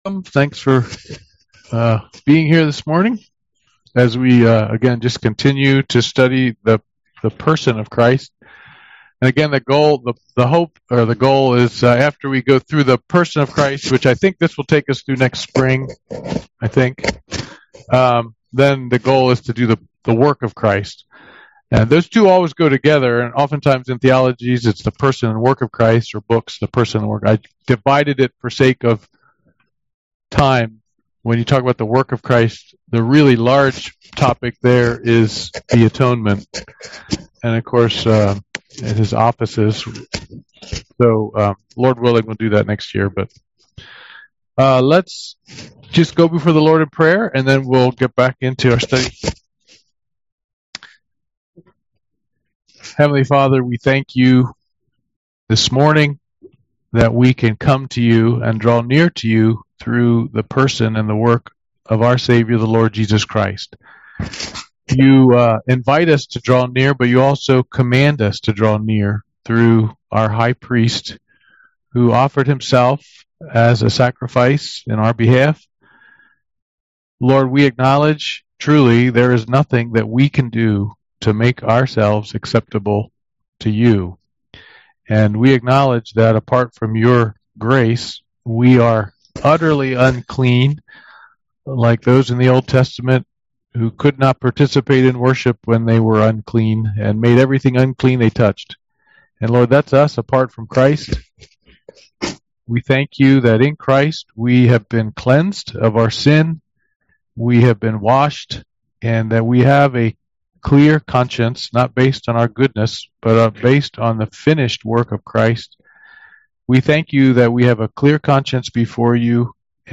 UN Service Type: Men's Bible Study « Sorrento